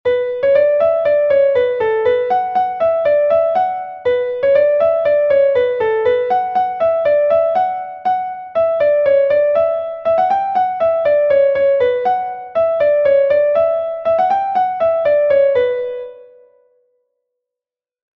Plin